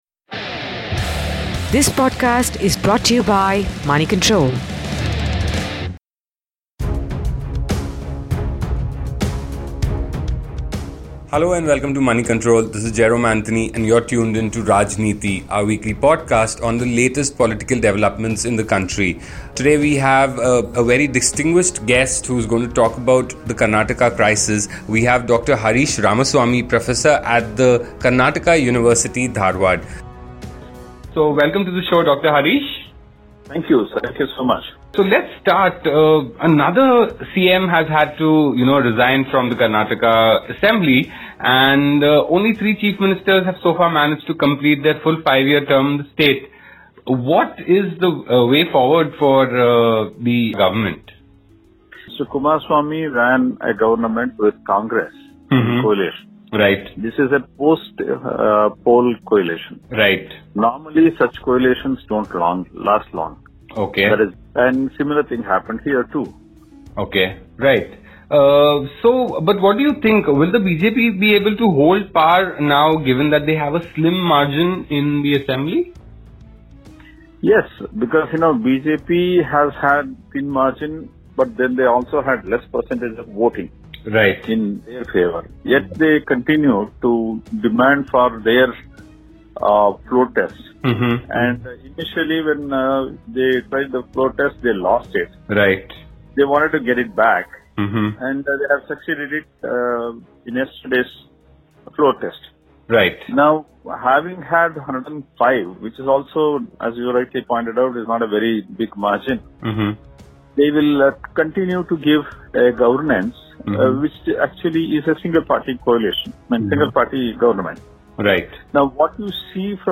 In a telephonic conversation